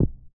VoxelEngine / res / content / base / sounds / steps / carpet_3.ogg
carpet_3.ogg